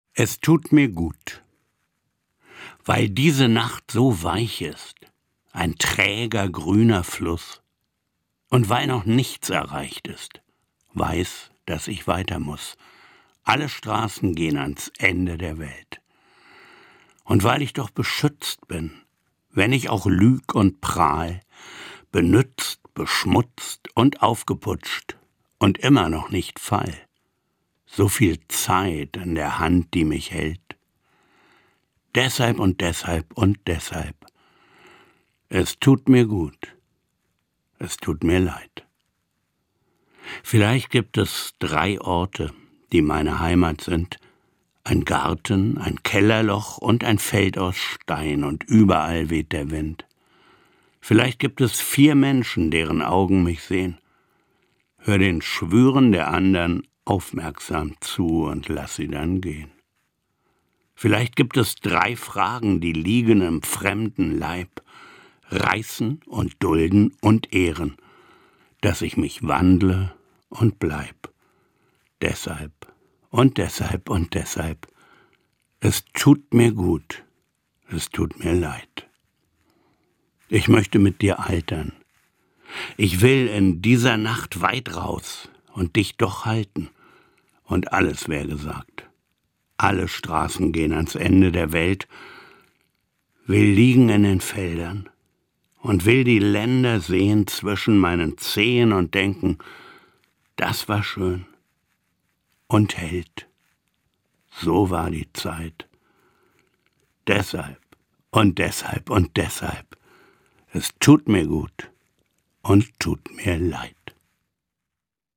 Gelesen von Manfred Maurenbrecher.